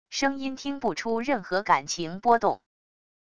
声音听不出任何感情波动wav音频